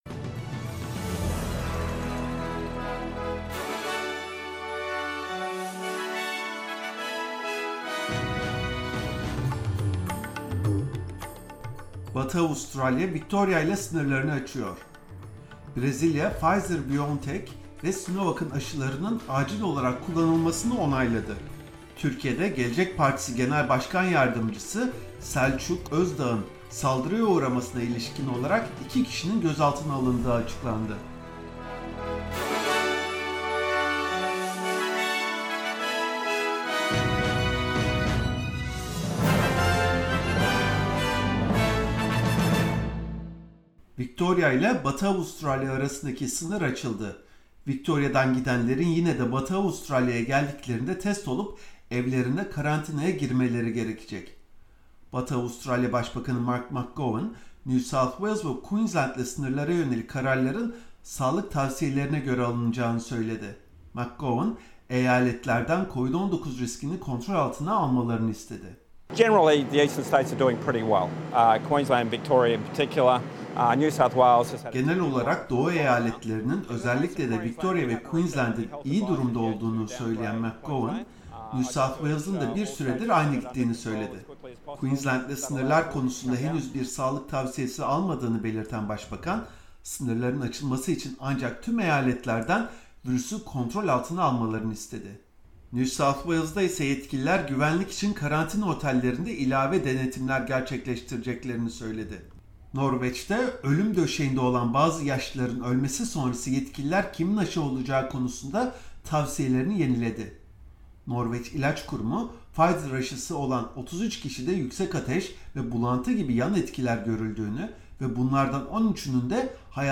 SBS Türkçe Haberler 18 Ocak